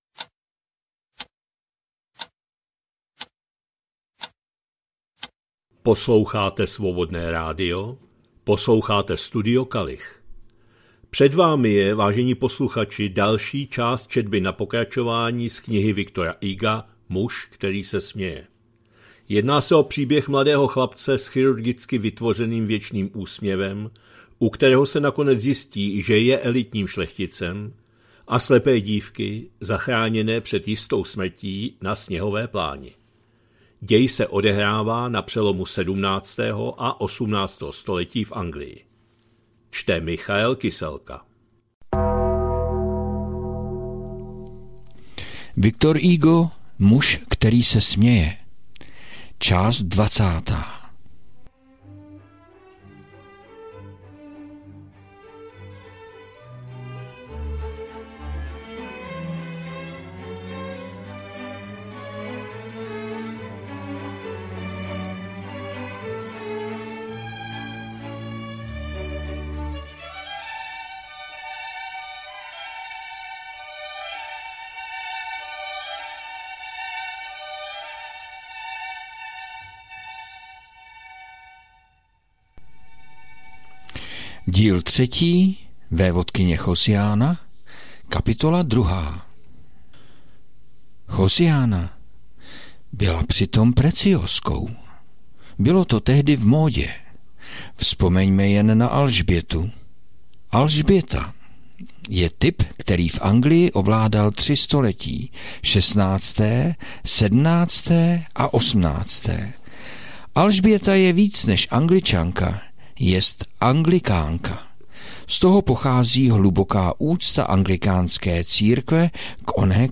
2025-07-21 - Studio Kalich - Muž který se směje, V. Hugo, část 20., četba na pokračování